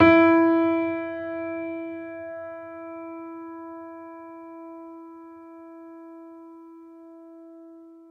piano-sounds-dev
e3.mp3